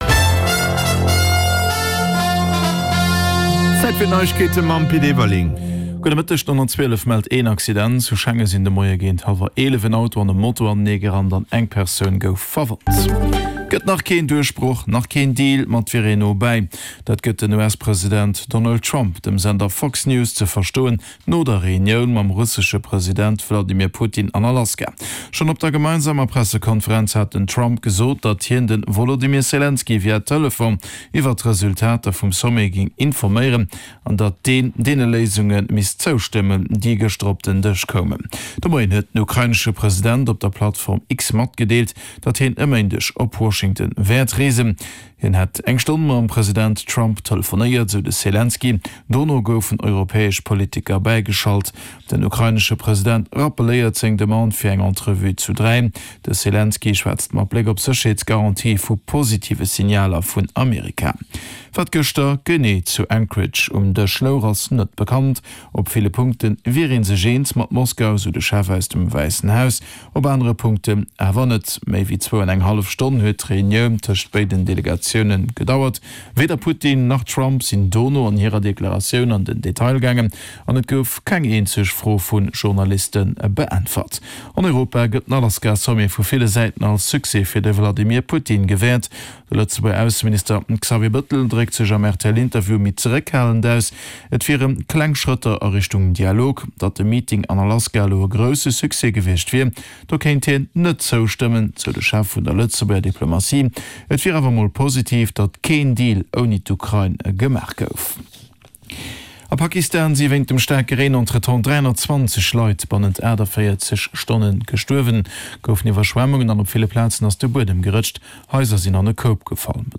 Play Rate Listened List Bookmark Get this podcast via API From The Podcast Den News Bulletin mat allen Headlines aus Politik, Gesellschaft, Economie, Kultur a Sport, national an international Join Podchaser to...